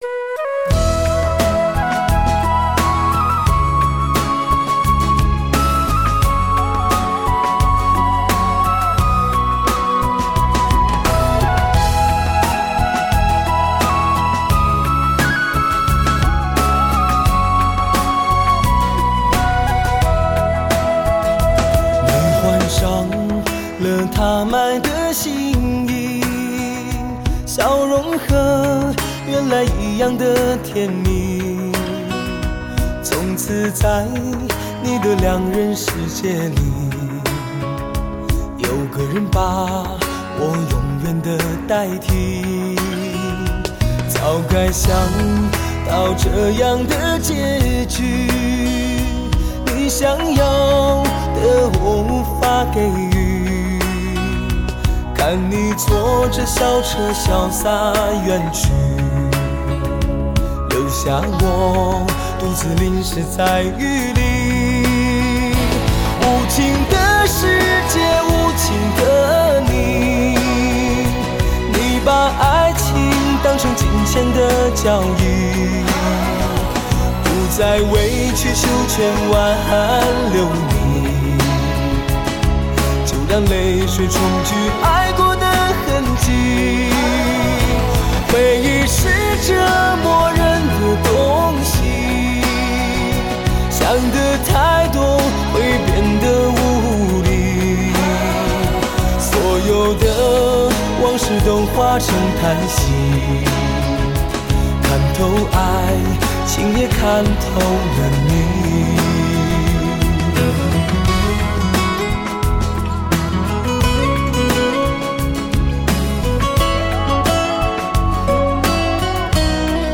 品尝浓浓的HI-FI味道
无尽的遐思伴着酒液流入喉管，让人不知醉于音乐还是醉于美酒，流溢着甜美与成熟之美，悠扬着淡淡的忧愁与丝丝的庸懒。